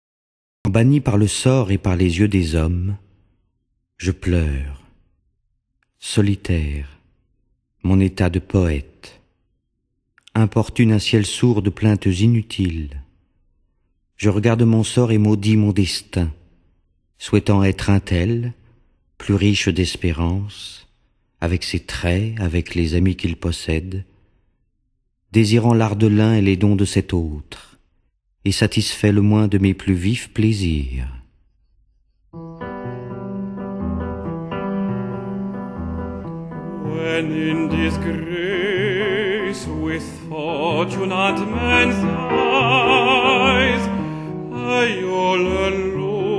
Voix chantées anglaises soprano
ténor
Voix parlée française
Piano